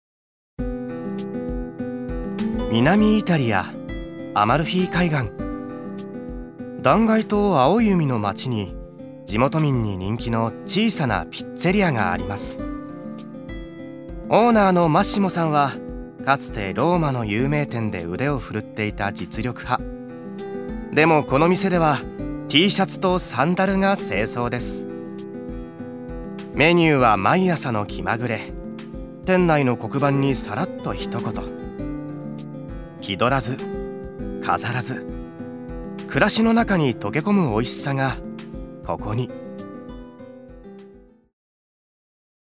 所属：男性タレント
ナレーション３